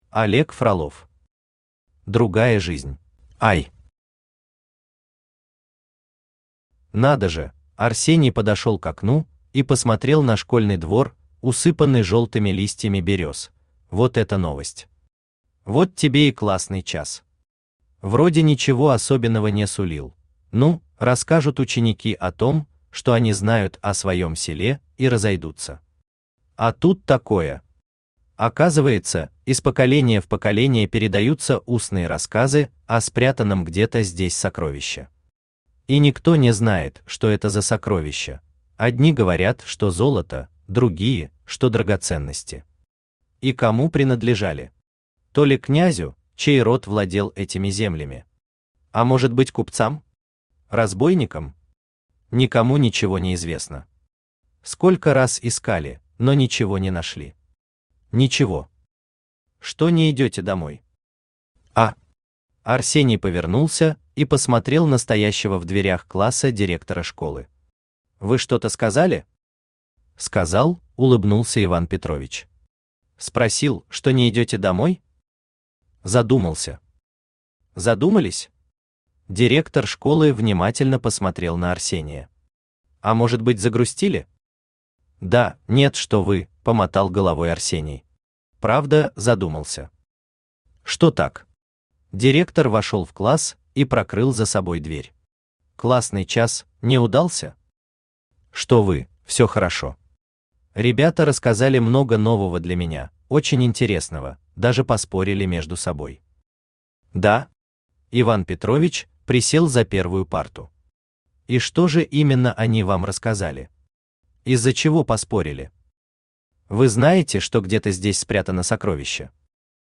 Аудиокнига Другая жизнь | Библиотека аудиокниг
Aудиокнига Другая жизнь Автор Олег Васильевич Фролов Читает аудиокнигу Авточтец ЛитРес.